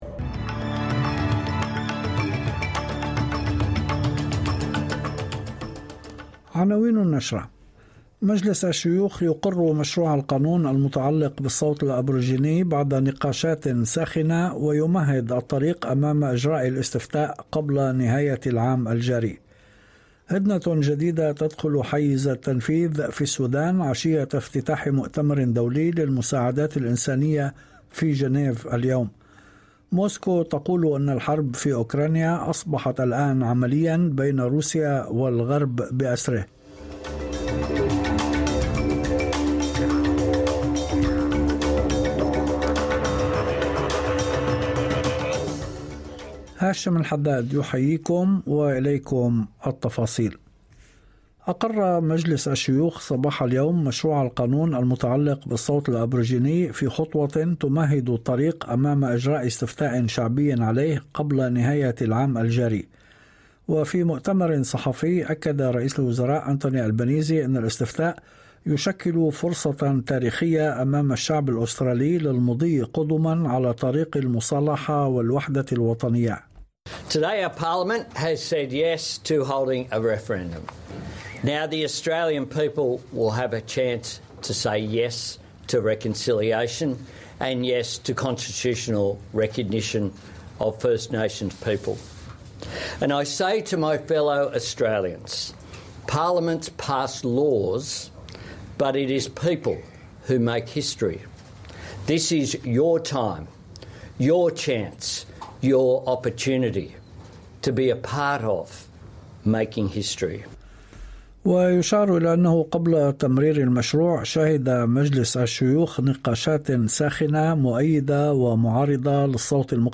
نشرة أخبار المساء 17/06/2023